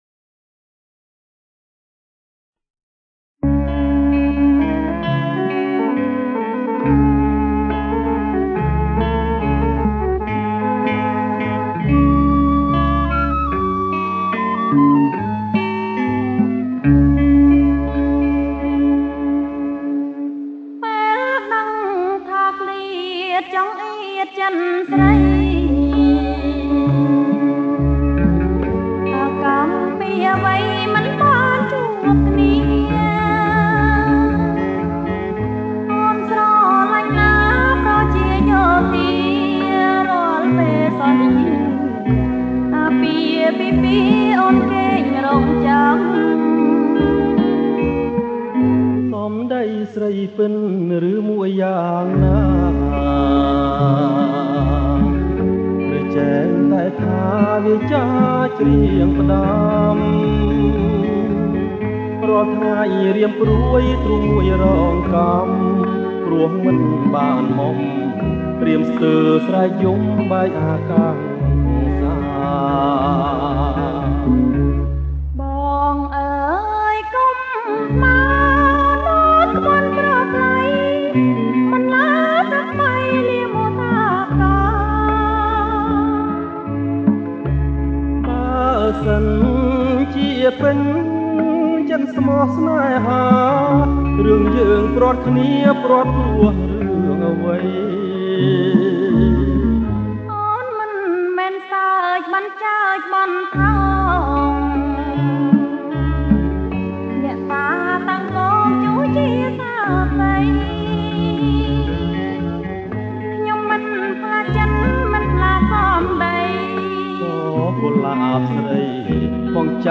• ប្រគំជាចង្វាក់ Bolero Lent